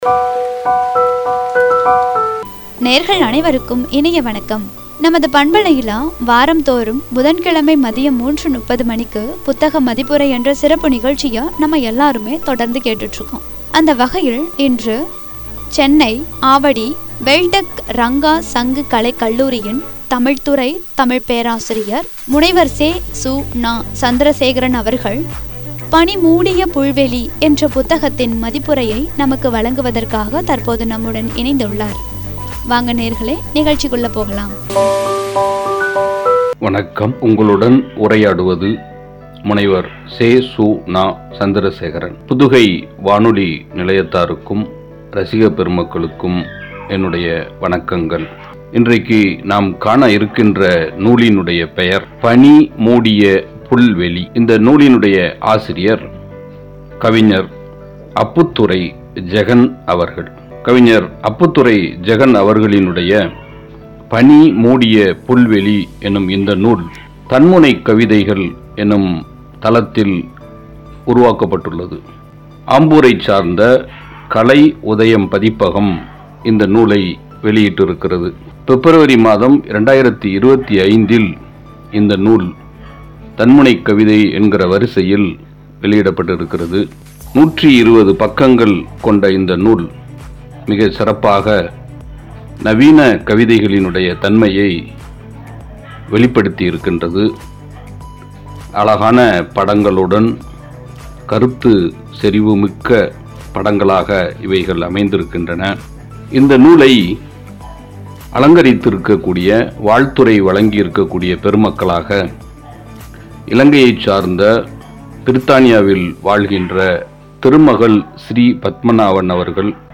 (புத்தக மதிப்புரை பகுதி 99) “பனி மூடிய புல்வெளி” என்ற தலைப்பில் வழங்கிய உரை